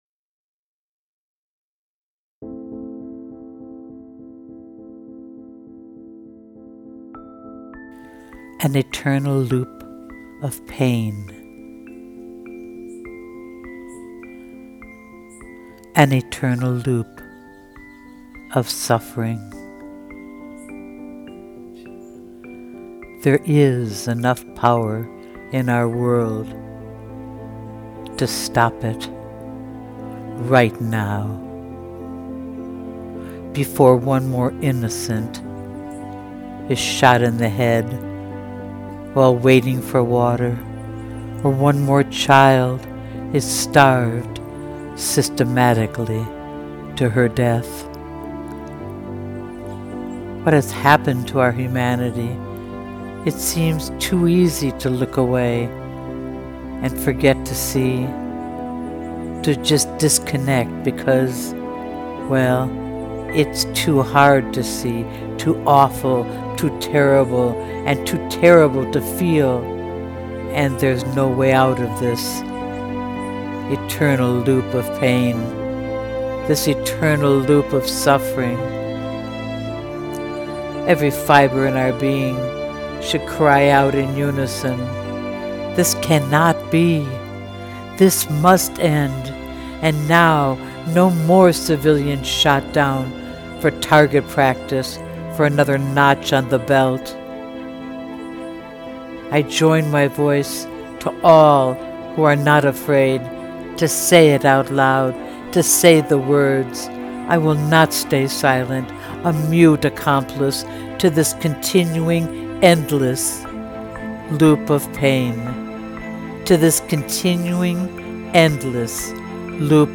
Beautifully narrated. 🙏